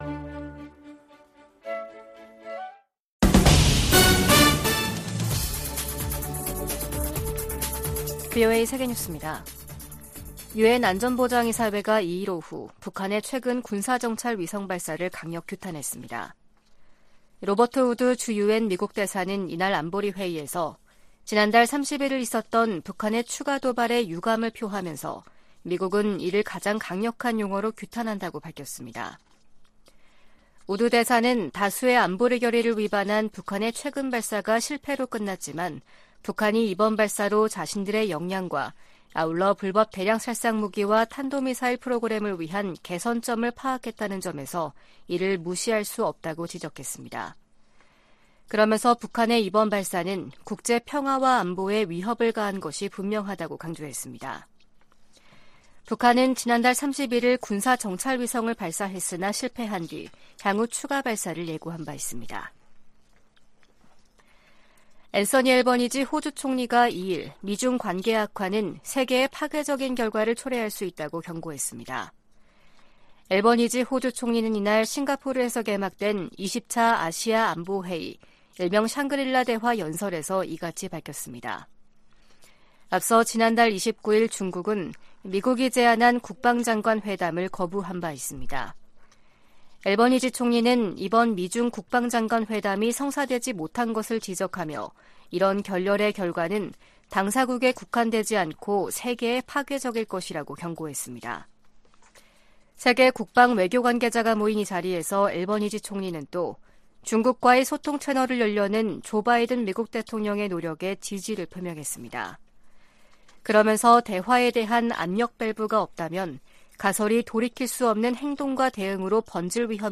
VOA 한국어 아침 뉴스 프로그램 '워싱턴 뉴스 광장' 2023년 6월 3일 방송입니다. 유엔 안보리가 미국의 요청으로 북한의 위성 발사에 대한 대응 방안을 논의하는 공개 회의를 개최합니다. 미국과 한국 정부가 북한 해킹 조직 '김수키'의 위험성을 알리는 합동주의보를 발표했습니다.